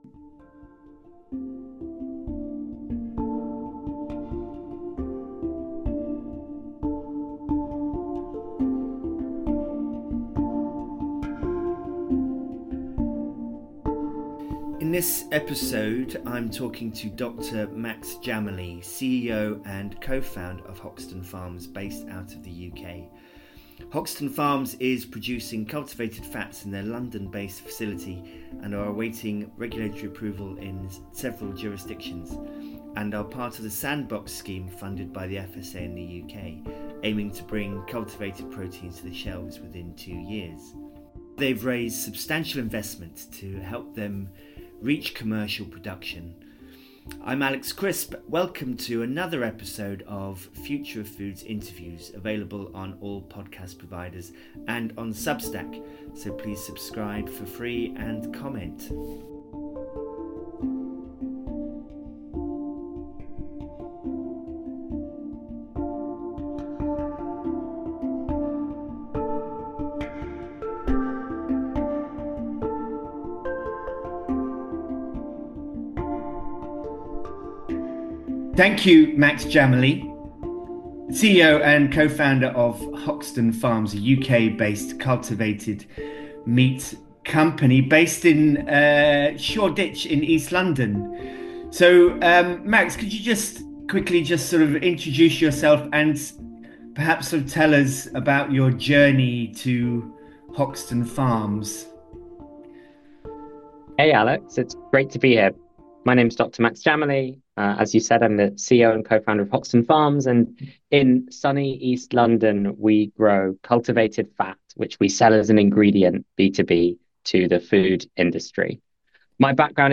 Future of Foods Interviews - Alt Proteins, Cell Agriculture, an End to Factory Farming. – Podcast